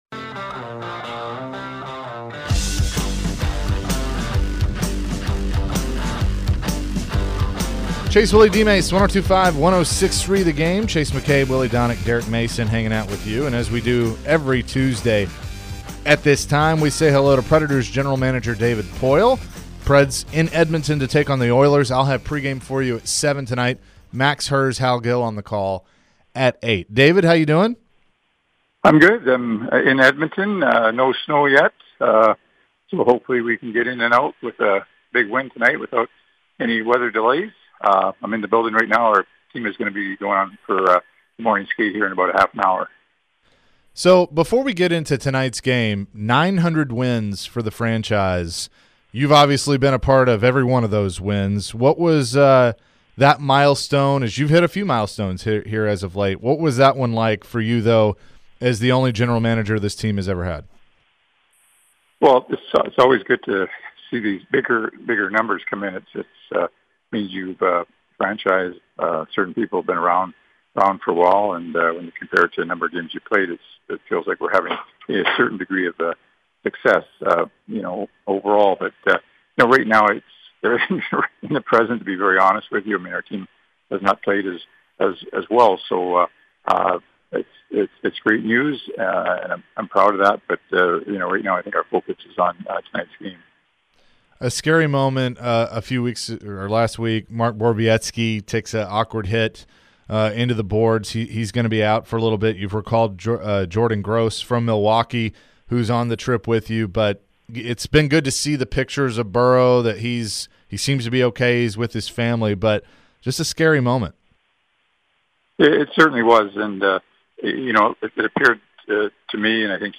David Poile Full Interview (11-01-22)